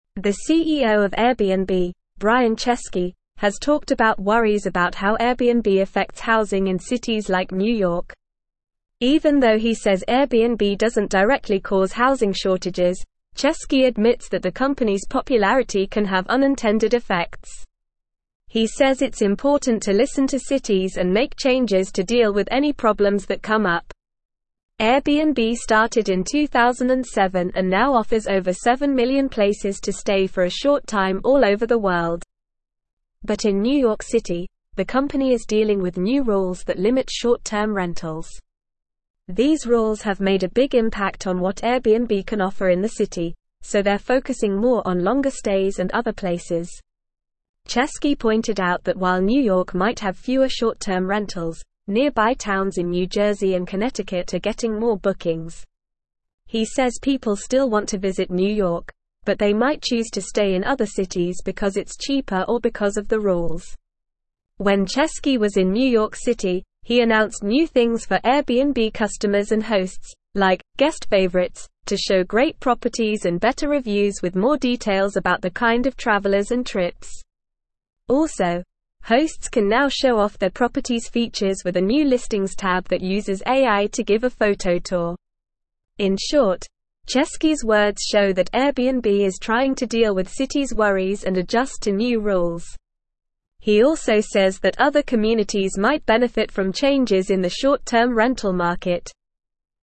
Normal
English-Newsroom-Upper-Intermediate-NORMAL-Reading-Airbnb-CEO-Addresses-Housing-Concerns-Adapts-to-Regulations.mp3